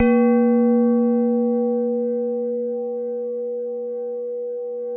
WHINE  A#1-L.wav